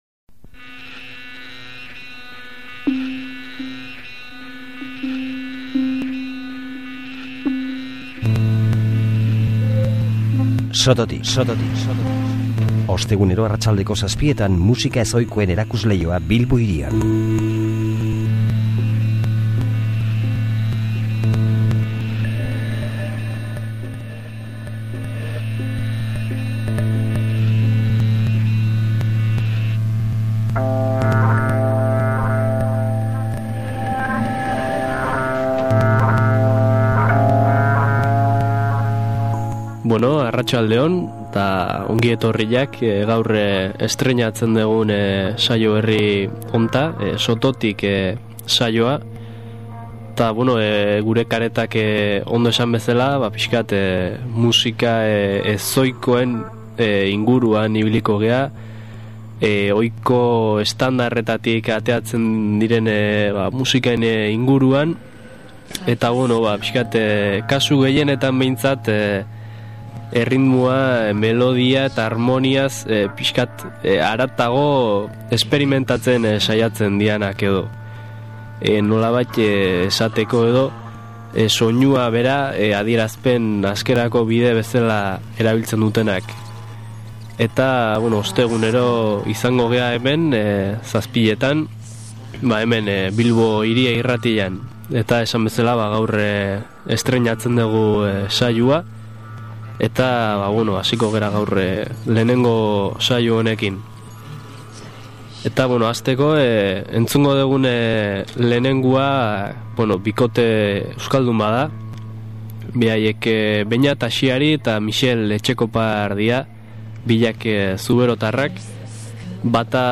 Ahotsa
Txistua eta ahotsa
1999an Donostiako Plaza jaialdian grabaturiko inprobisazioa